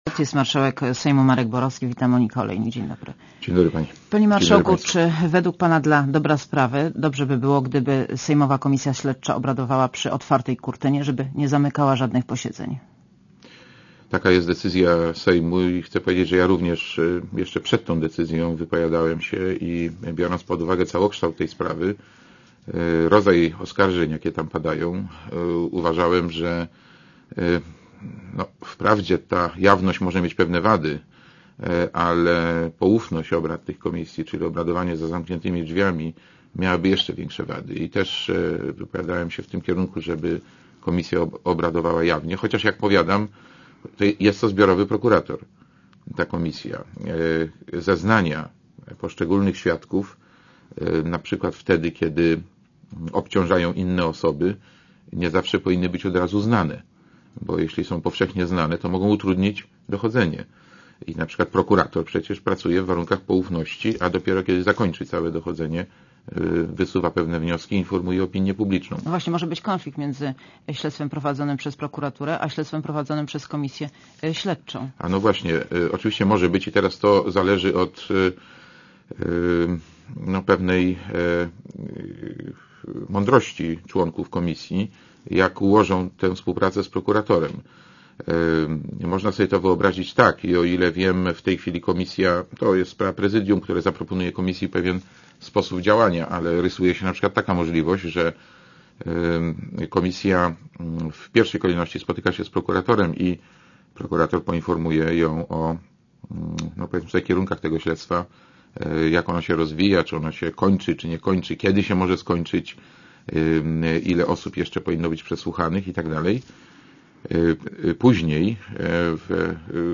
Monika Olejnik rozmawia z Markiem Borowskim - marszałkiem Sejmu